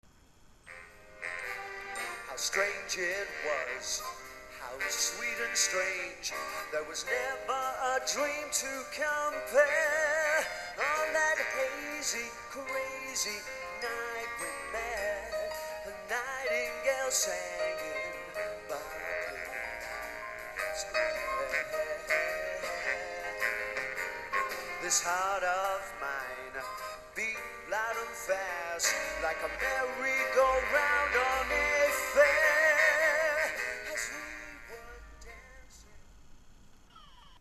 Limahl was invited to join us in the Wycombe Swan theatre on 8th Sept and very happily got into the 'swing' of things with a lovely rendition of 'A Nightingale Sang In Berkeley Square'
Click here for sound clip featuring Limahl singing 'A Nightingale Sang In Berkeley Square'
OrchestraLimahl02.mp3